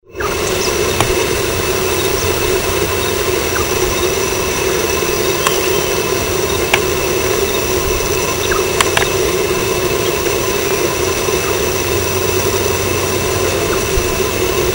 Rhinocrypta lanceolata
Lo escuchamos bastante, estaba lejos, en principio pensamos que era un zorzal, pero con escuchar los cantos, nos dimos cuenta con certeza de que se trataba de esta especie.
Nome em Inglês: Crested Gallito
Detalhada localização: Laguna Guatraché
Condição: Selvagem
Certeza: Gravado Vocal